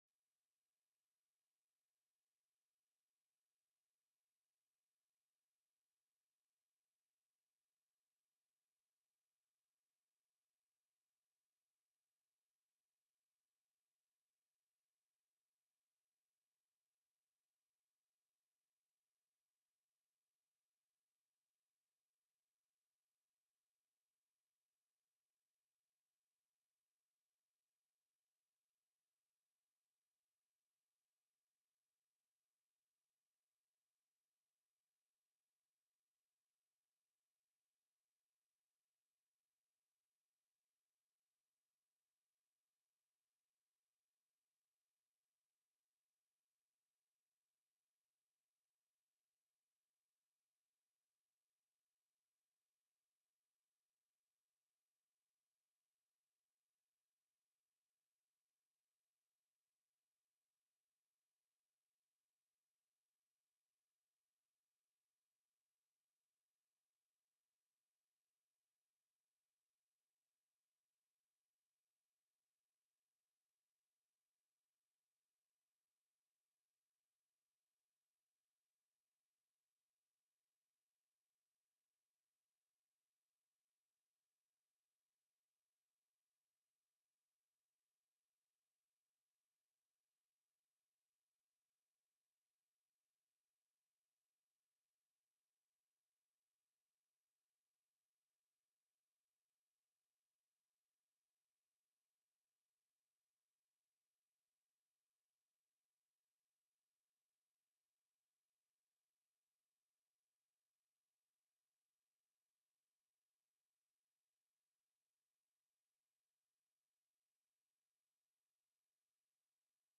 The audio recordings are captured by our records offices as the official record of the meeting and will have more accurate timestamps.
HB 78 RETIREMENT SYSTEMS; DEFINED BENEFIT OPT. TELECONFERENCED